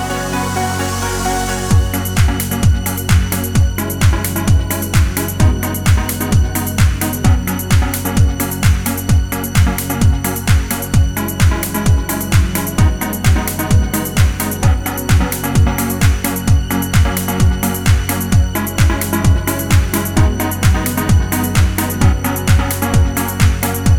Two Semitones Down Dance 3:27 Buy £1.50